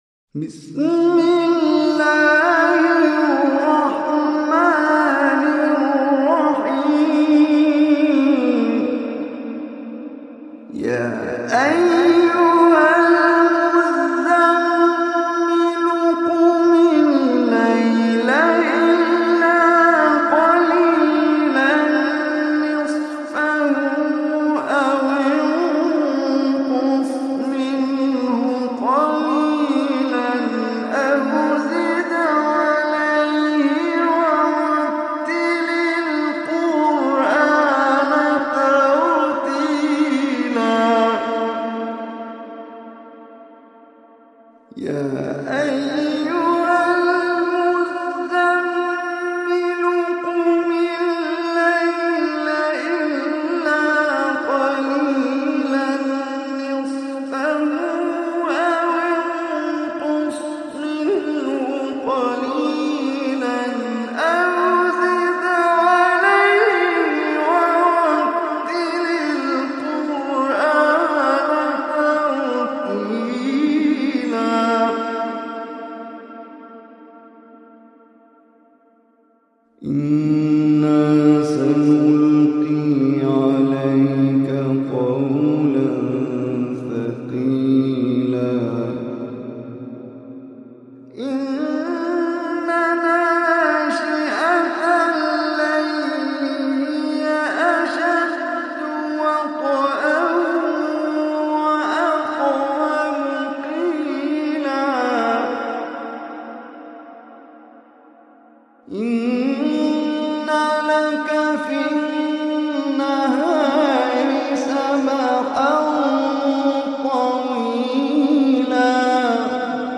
Surah Muzammil Recitation by Omar Hisham Arabi
Surah Muzammil, is 73 surah of Holy Quran. Listen or play online mp3 tilawat / recitation in arabic in the beautiful voice of Omar Hisham Al Arabi.